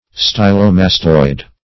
Search Result for " stylomastoid" : The Collaborative International Dictionary of English v.0.48: Stylomastoid \Sty`lo*mas"toid\, a. (Anat.) Of or pertaining to the styloid and mastoid processes of the temporal bone.
stylomastoid.mp3